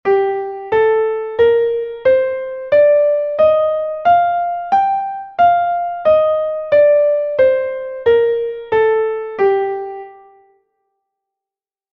Sol+Menor (audio/mpeg)